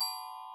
glock_A_4_2.ogg